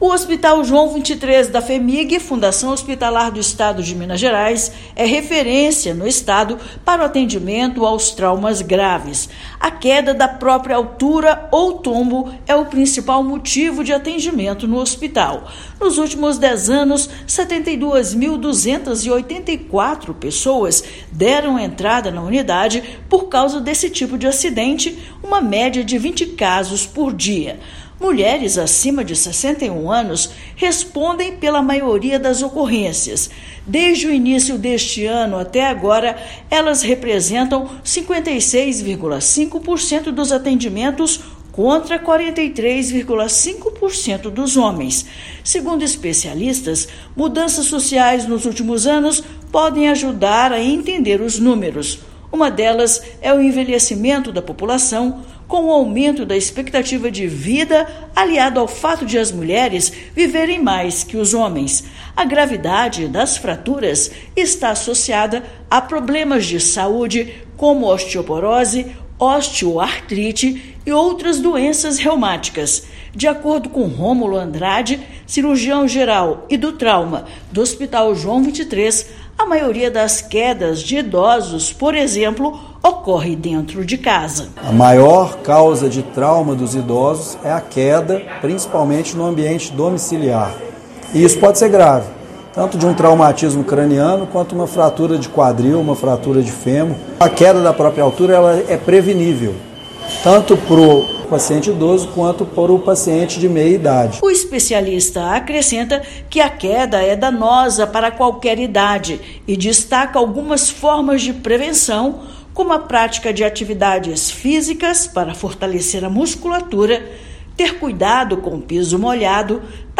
Idosas são as principais vítimas de tombos na idade adulta; especialistas ensinam dicas para prevenir acidentes. Ouça matéria de rádio.